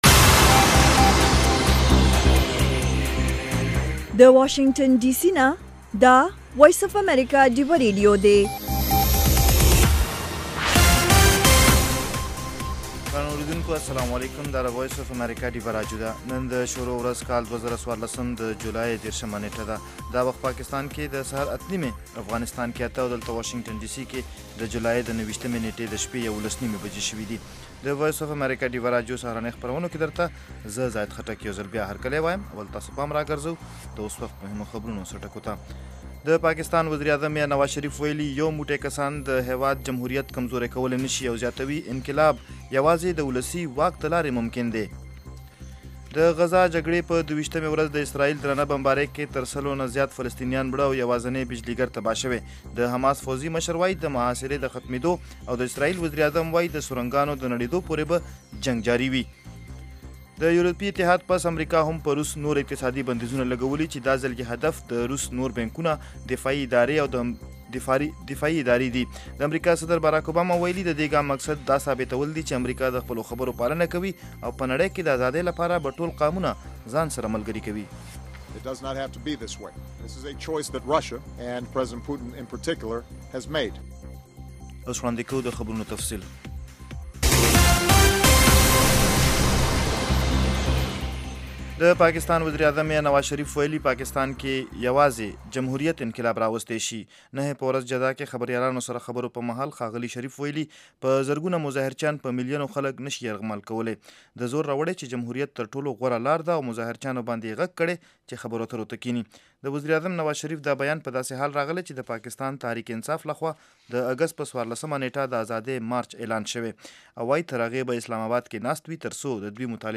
خبرونه - 0330